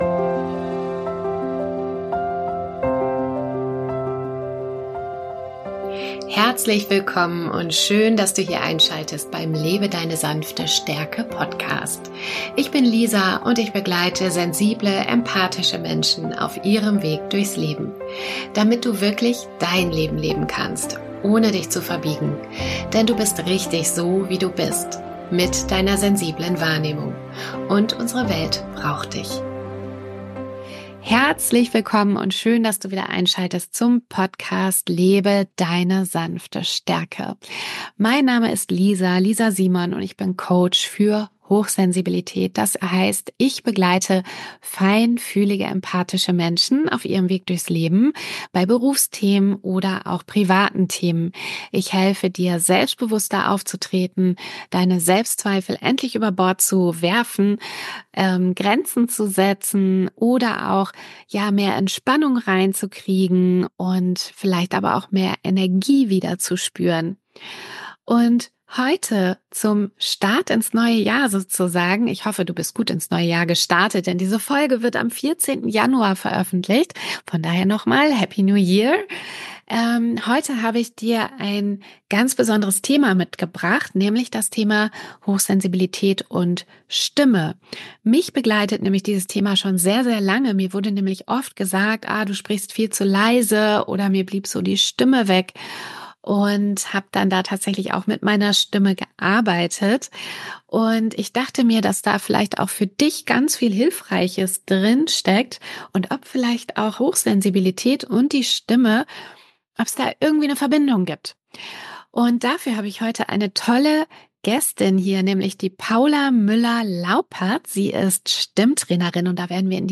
Gemeinsam sprechen wir darüber, wie Deine Stimme ein mächtiges Werkzeug sein kann, um Grenzen zu setzen, selbstbewusster aufzutreten und Deine innere Stärke nach außen zu tragen – beruflich wie privat.